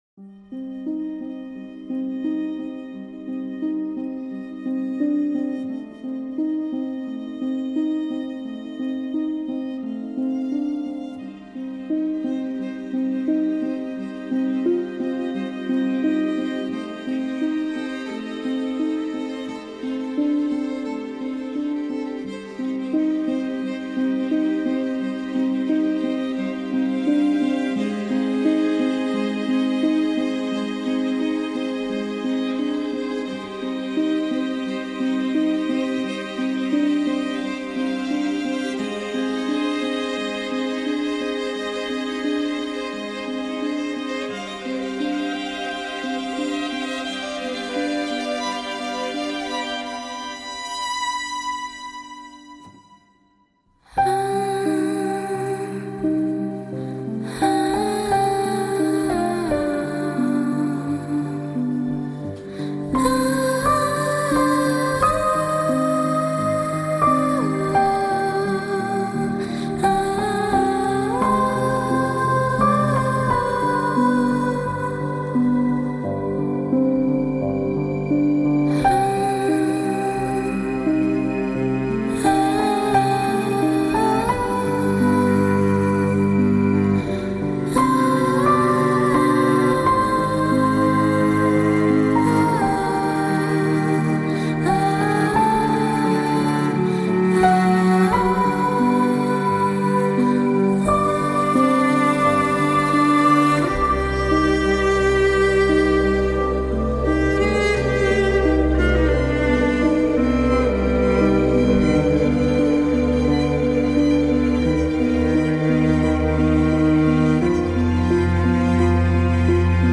Un point Bleu Pâle (pour montrer un autre aperçu du chant)